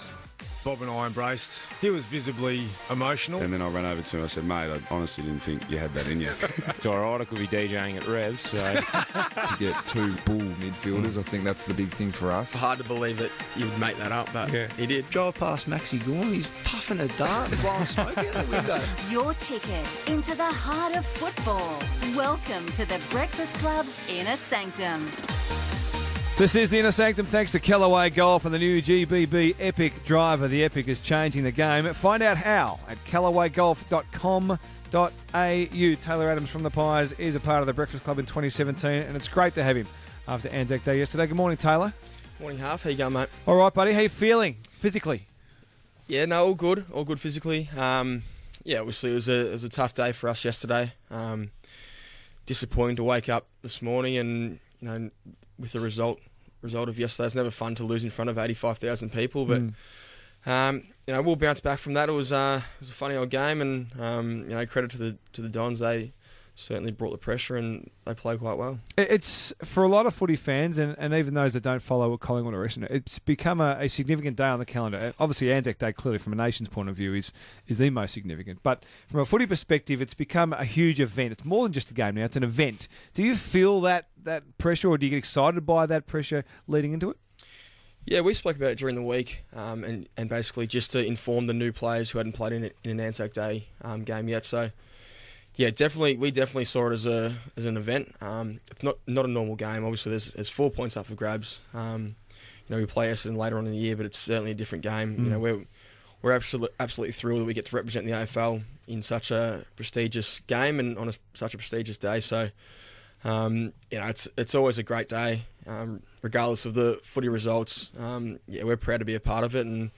Radio: Taylor Adams on RSN
Taylor Adams joins RSN's 'The Breakfast Club' on Wednesday morning following Collingwood's 18-point loss to Essendon on ANZAC Day.